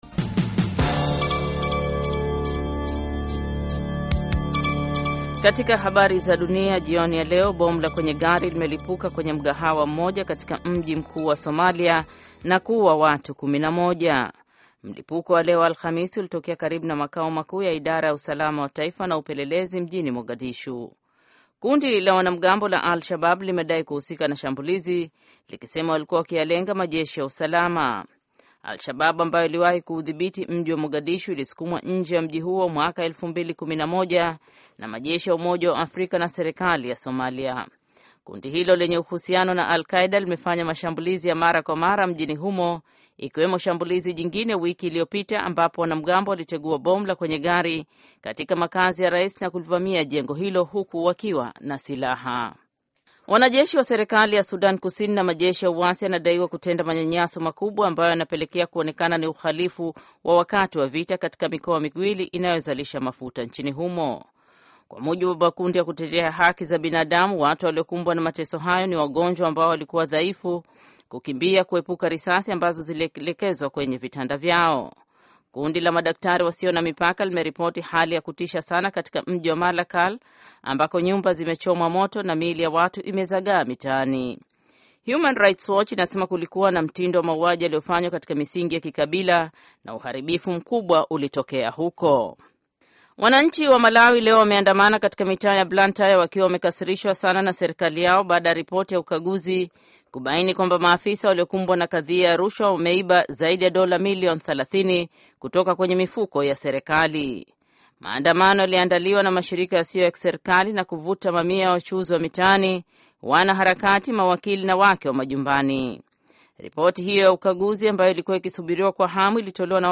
Taarifa ya Habari VOA Swahili - 5:17